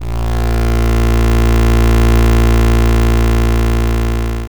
gliding.wav